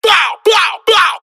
MZ Vox [Flame](1).wav